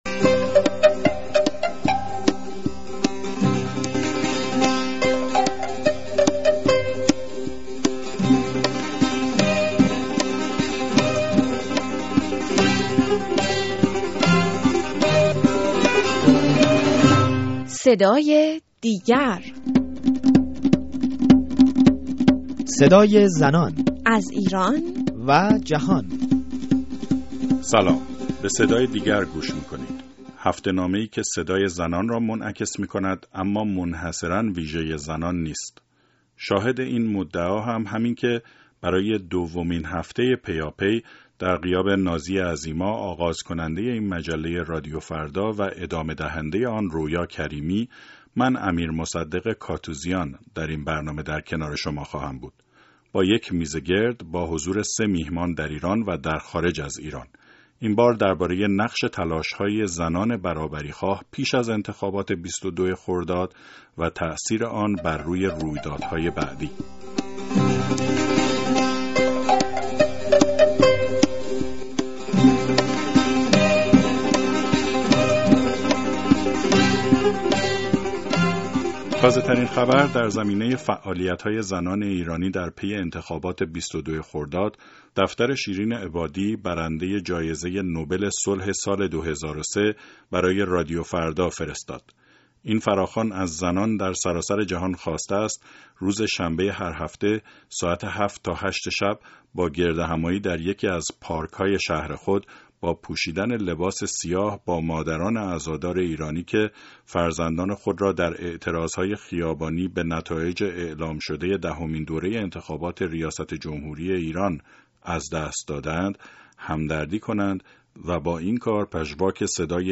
میزگرد رادیویی برنامه صدای دیگر